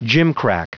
Prononciation du mot gimcrack en anglais (fichier audio)
gimcrack.wav